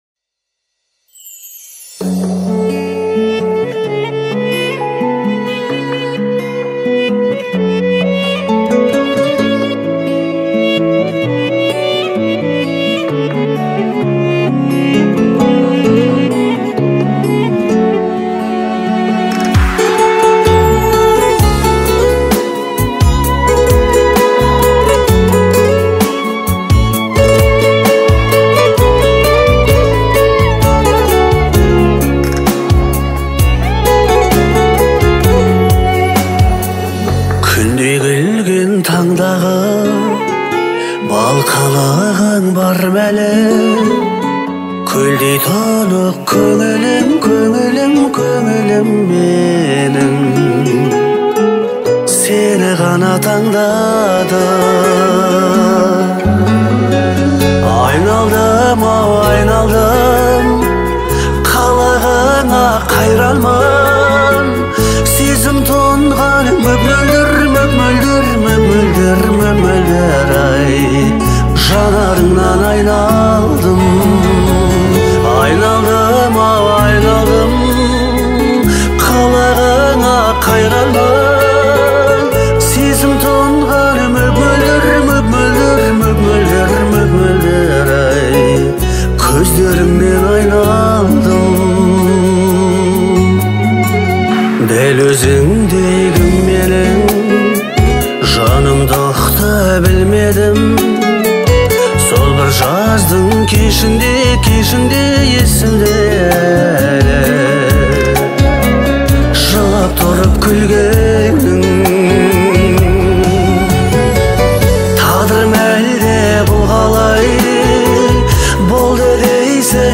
Звучание песни отличается мелодичностью и душевностью